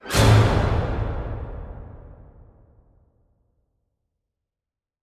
Jumpscare_12.wav